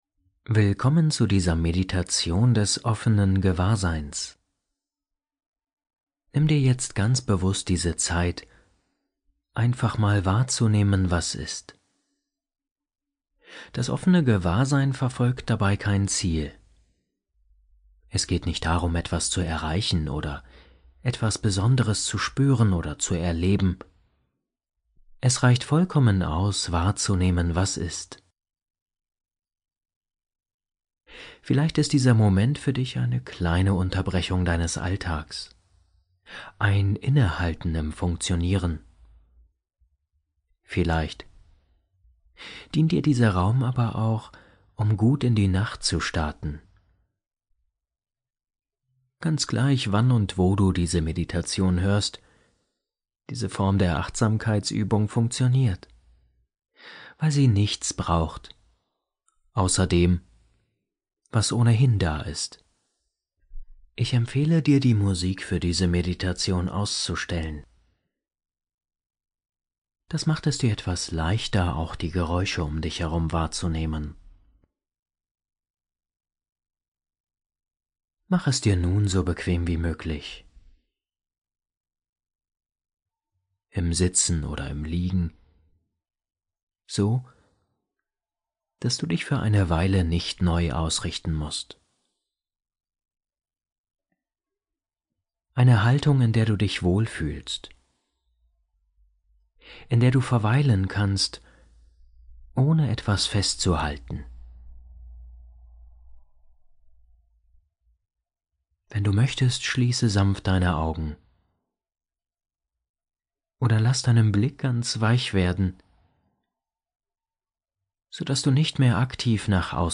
Offenes Gewahrsein – Geführte Achtsamkeitsmeditation ohne Ziel ~ Entspannungshelden – Meditationen zum Einschlafen, Traumreisen & Entspannung Podcast